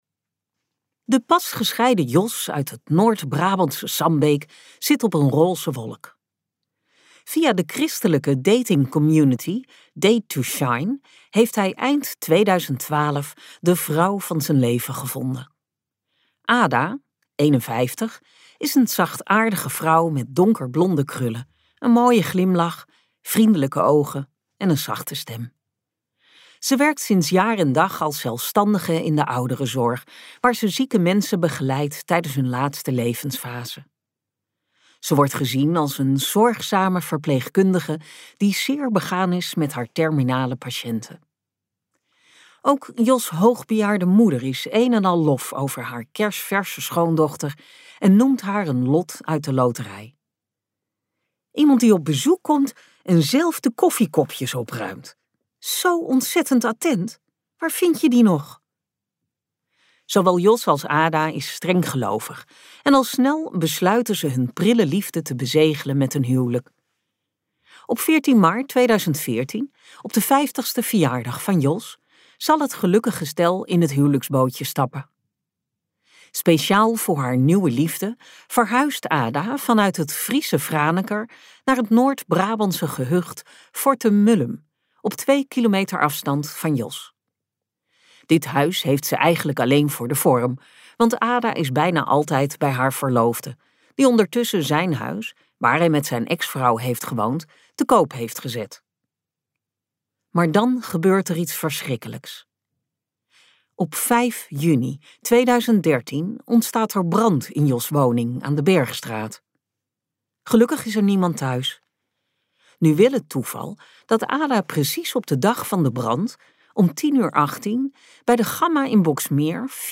De campingmoord luisterboek | Ambo|Anthos Uitgevers